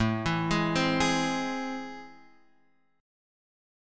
AmM7 chord